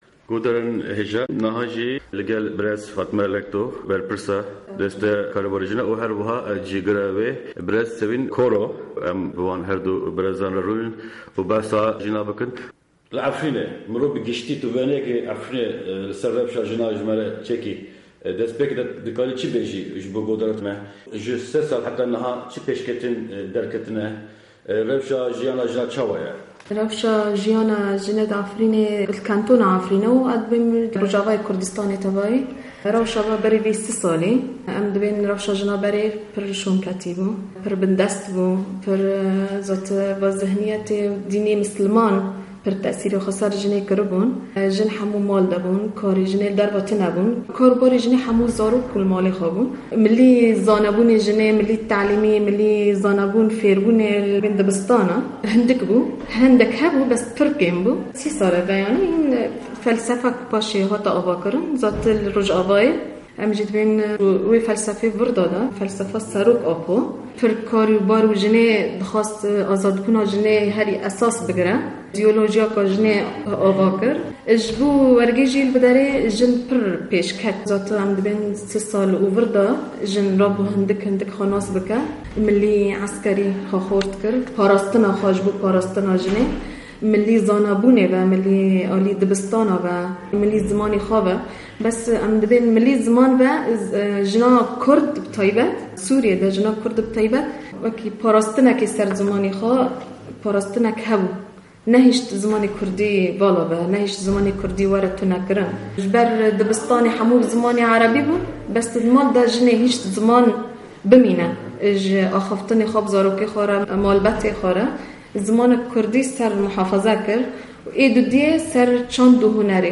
Hevpeyvîneke Taybet ser Rewşa Jinên Kurd li Efrînê
Seroka Desteyê Jinan û Malbatê ya Kantona Efrînê Fatma Lekto û cîgirê wê Sevîn Koro ser rewşa jinan ya li herêma Efrînê agahîyên girîng didin